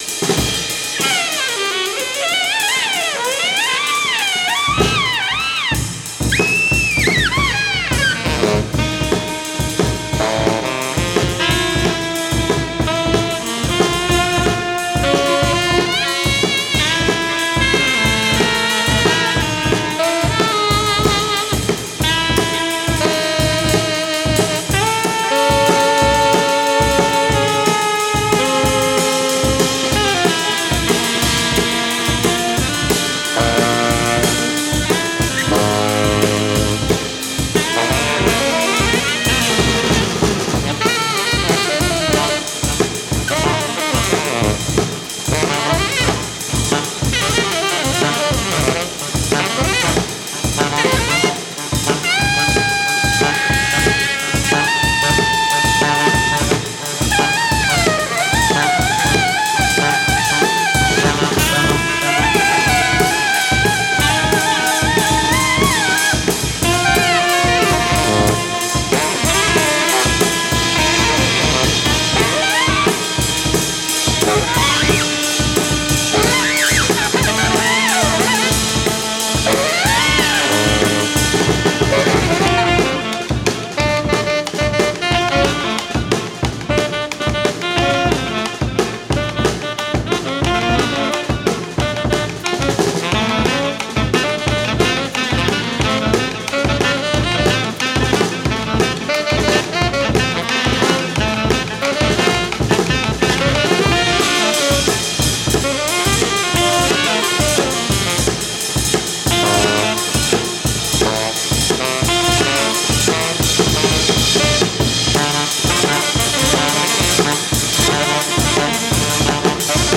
Recorded live at the 39th Street loft, Brooklyn.
drums
alto saxophone
Â tenor saxophone
Stereo (Pro Tools)